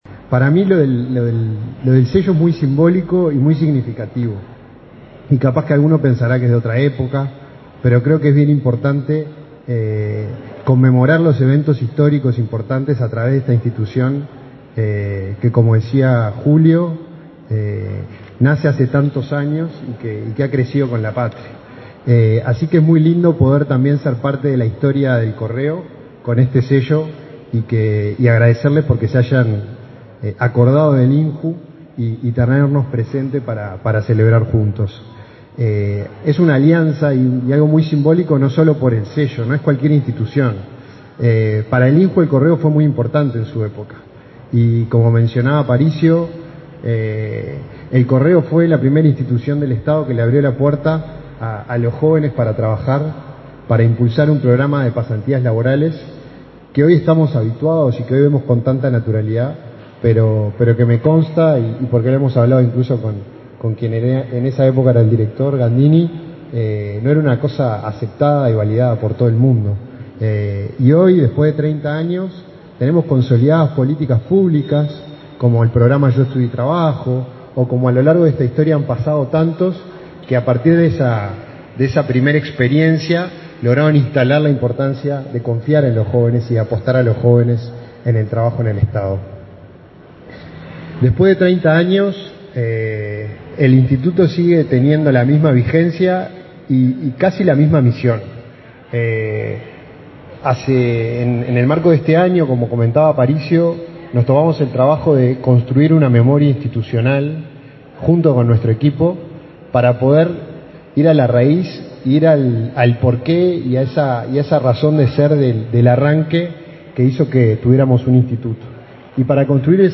Palabras del director del INJU, Felipe Paullier
El director del INJU, Felipe Paullier, participó este lunes 20 en la presentación de un sello conmemorativo por los 30 años de ese instituto.